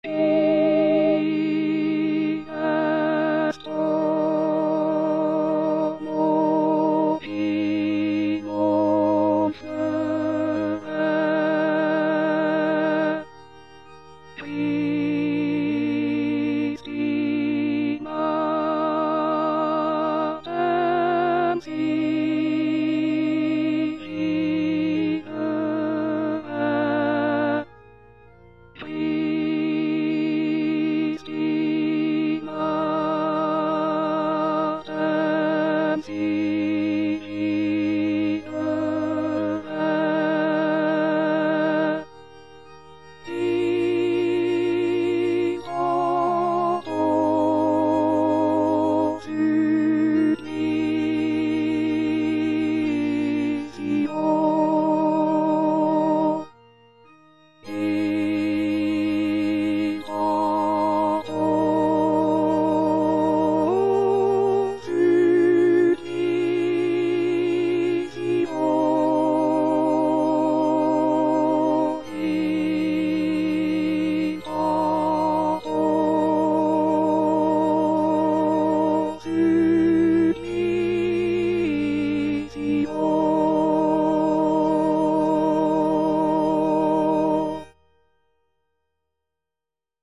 Parole 3: Mulier, ecce filius tuus        Prononciation gallicane (à la française)